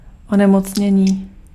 Ääntäminen
Synonyymit pathologie affection Ääntäminen France: IPA: [ma.la.di] Haettu sana löytyi näillä lähdekielillä: ranska Käännös Ääninäyte Substantiivit 1. nemoc {f} 2. choroba {f} 3. onemocnění Suku: f .